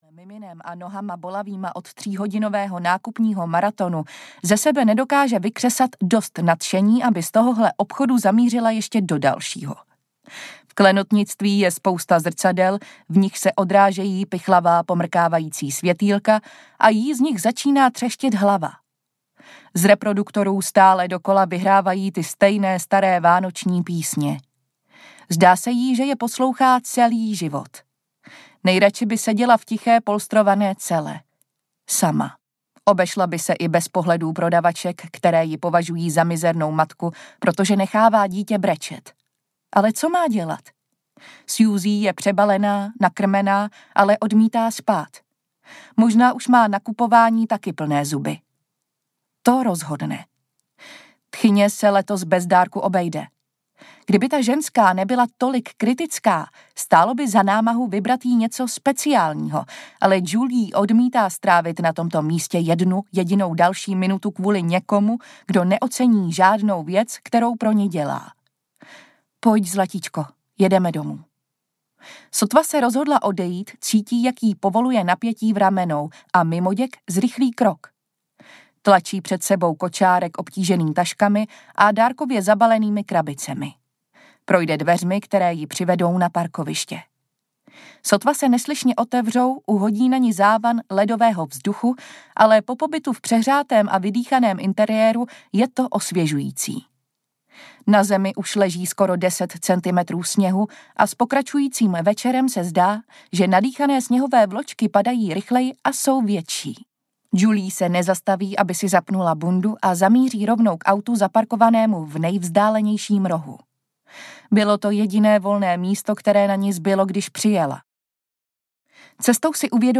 Mrazivé vyhlídky audiokniha
Ukázka z knihy